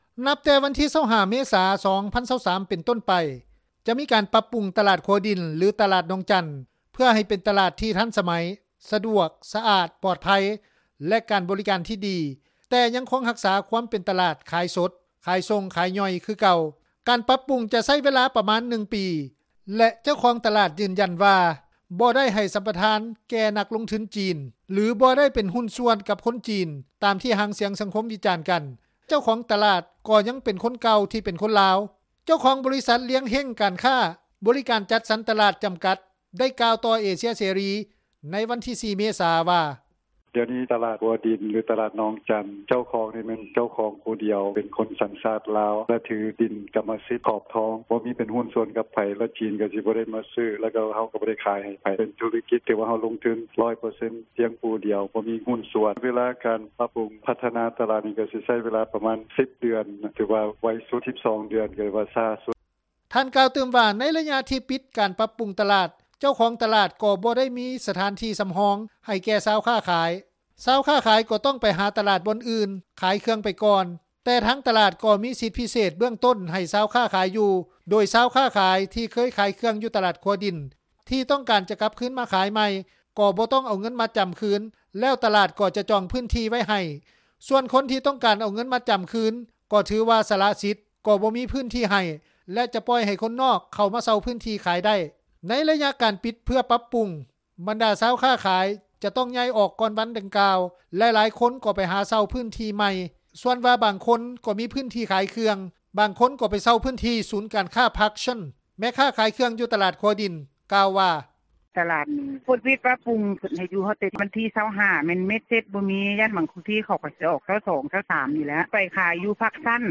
ແມ່ຄ້າຂາຍເຄື່ອງ ຢູ່ຕລາດຂົວດິນ ໄດ້ກ່າວ:
ປະຊາຊົນທີ່ອາໄສຢູ່ໃກ້ກັບຕລາດຂົວດິນ ໄດ້ກ່າວວ່າ: